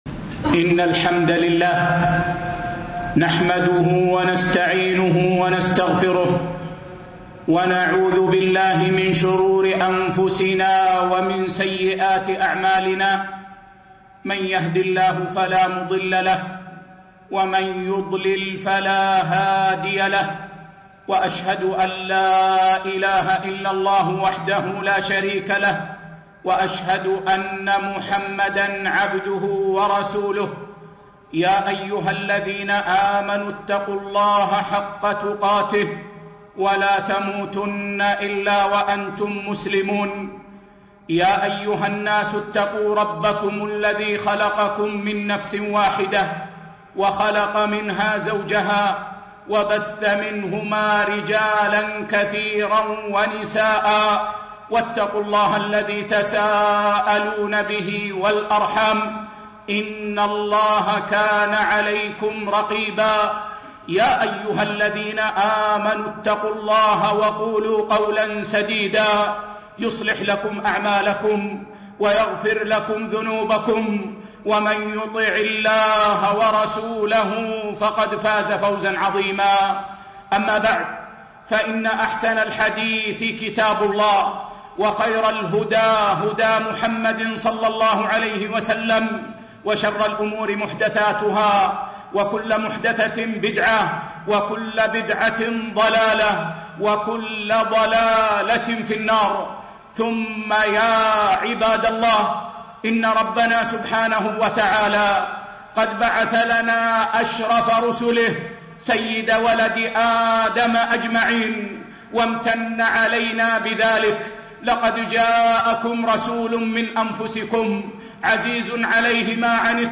خطبة - المنهج السليم للحياة الطيبة - دروس الكويت